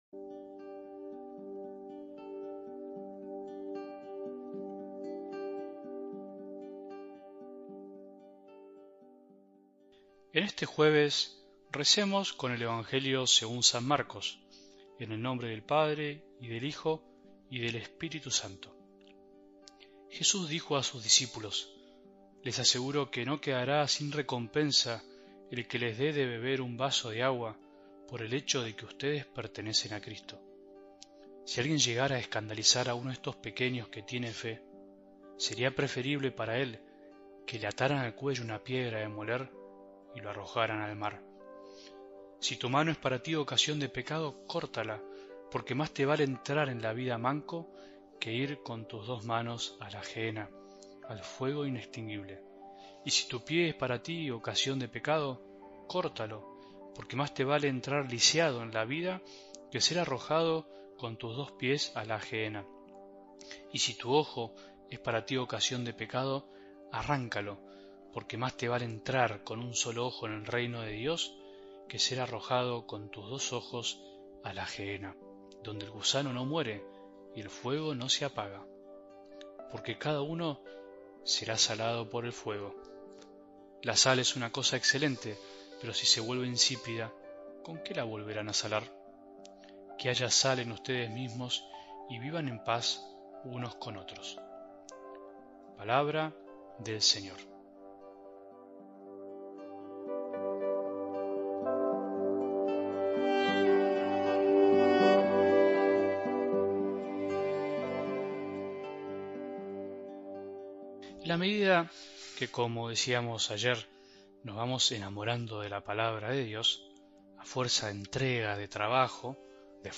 Reflexión
Música Disipulos Marcos Vidal